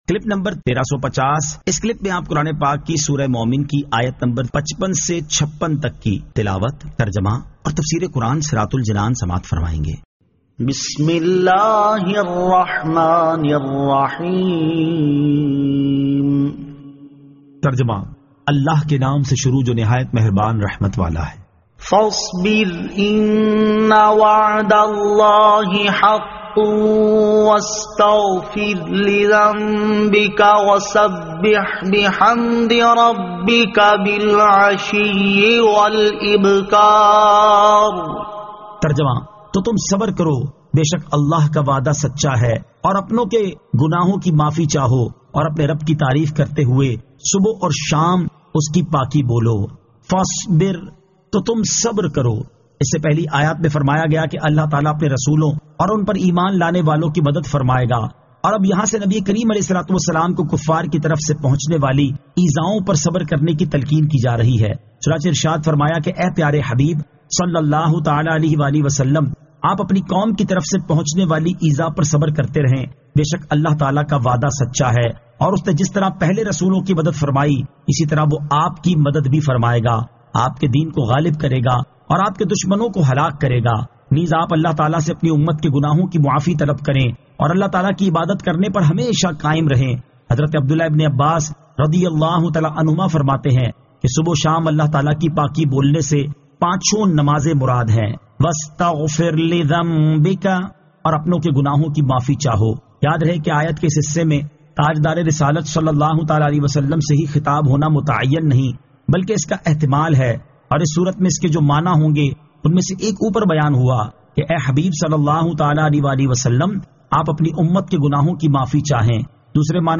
Surah Al-Mu'min 55 To 56 Tilawat , Tarjama , Tafseer